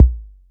Kick Beatboxer.wav